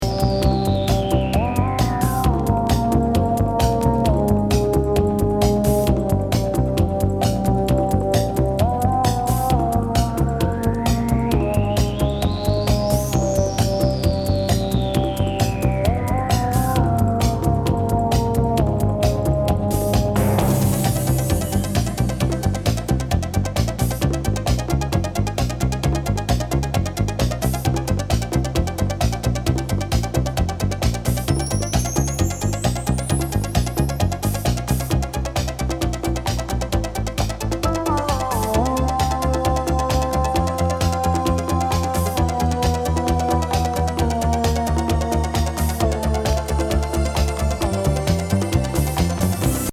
コズミック・シンセ・アルペジオにヴォコーダーの謎
ロマンチック・スペーシー・エレクトロ・ディスコ！スクリューも可！